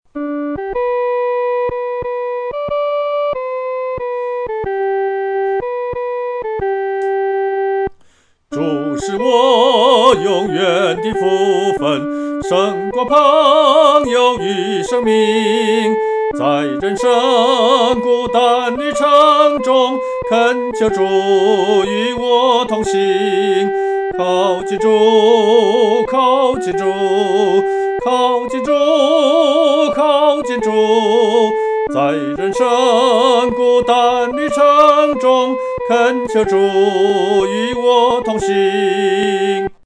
独唱（第一声）
靠近主-独唱（第一声）.mp3